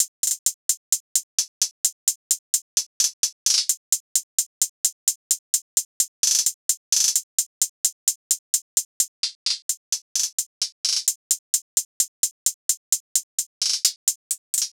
SOUTHSIDE_beat_loop_cut_hihat_130.wav